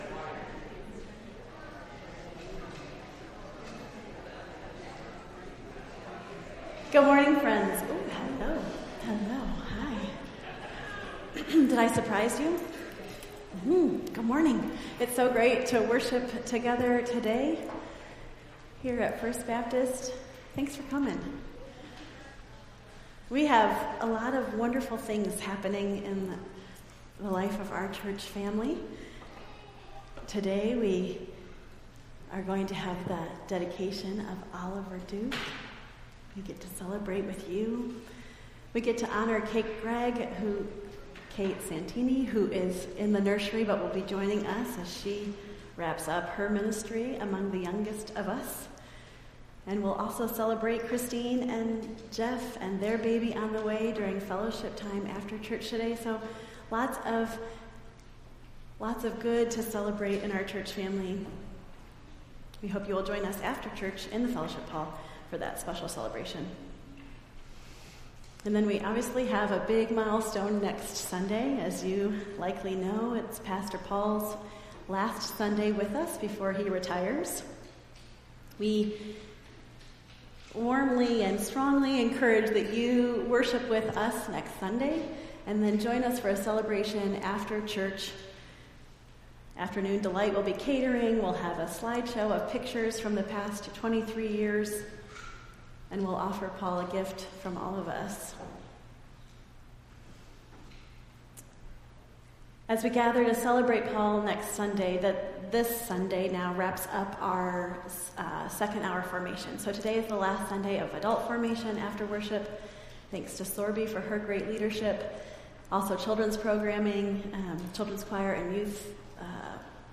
Entire June 9th Service